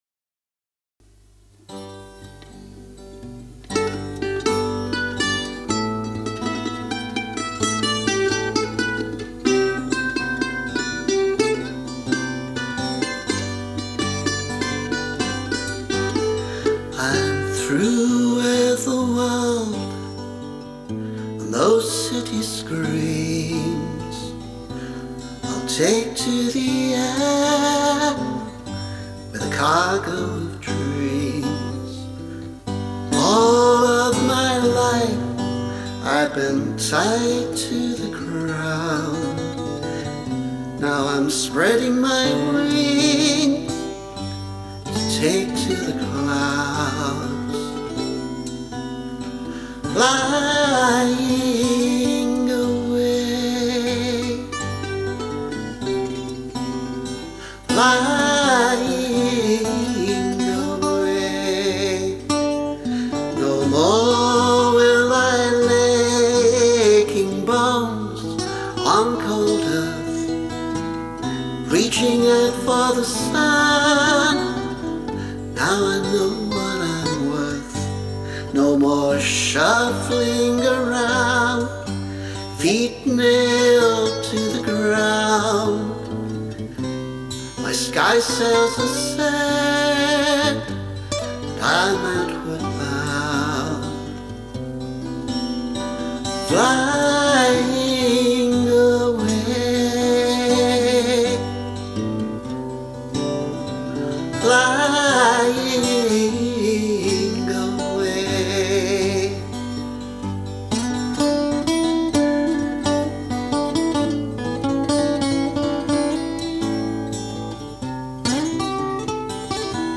Updraught [demo]